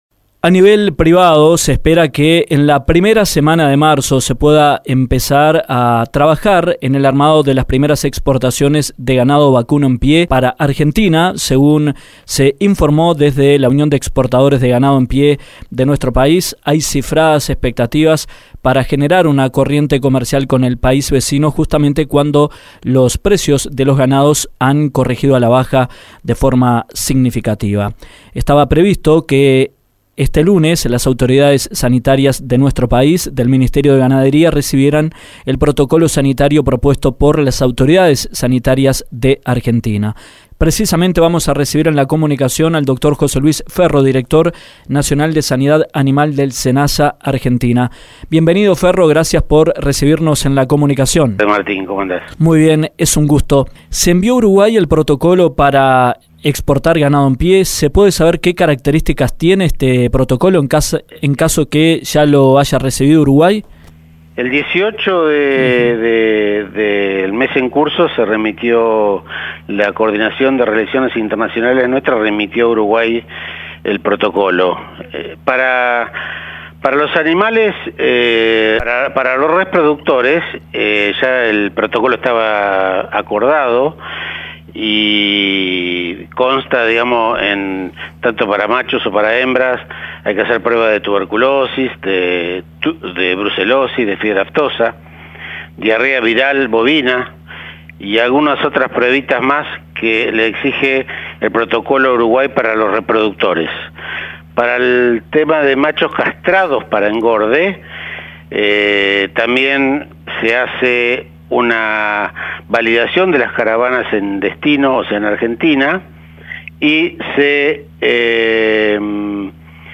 El director del Servicio Nacional de Sanidad y Calidad Agroalimentaria de Argentina, Dr. José Luis Ferro, en dialogo con Dinámica Rural, informó sobre las características del protocolo propuesto por el SENASA a las autoridades sanitarias del MGAP.